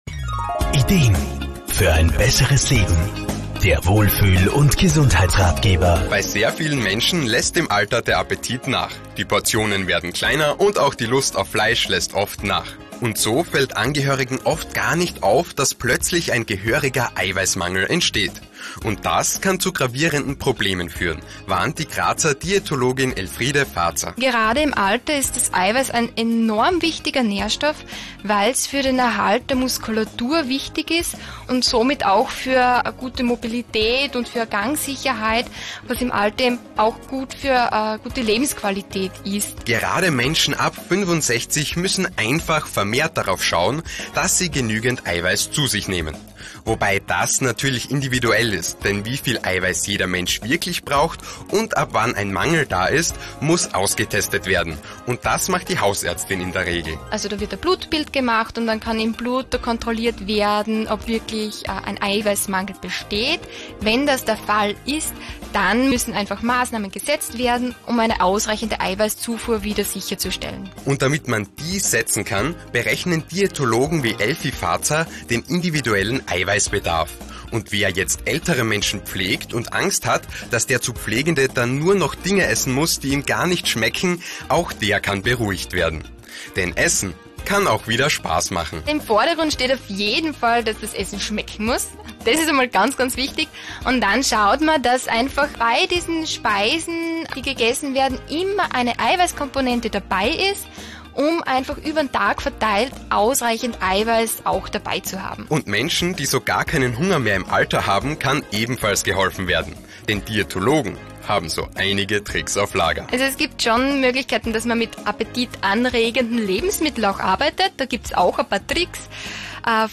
Diätologin